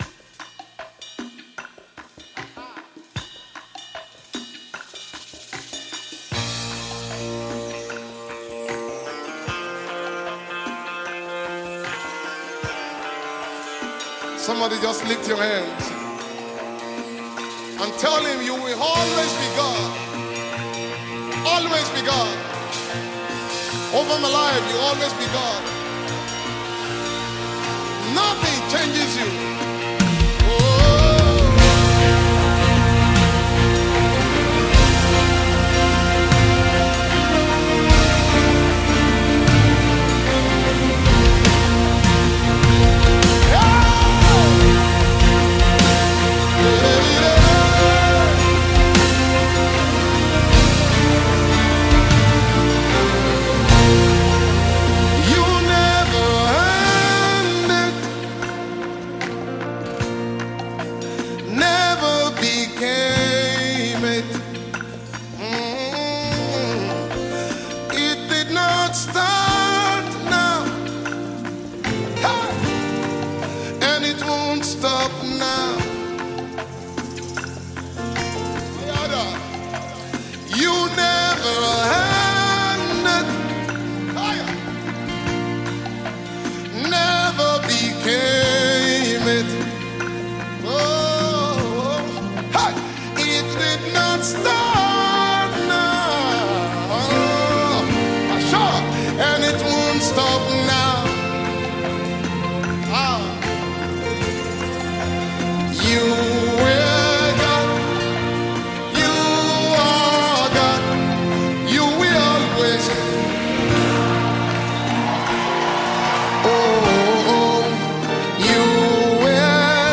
Talented Nigerian gospel singer and songwriter